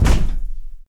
FEETS 2   -L.wav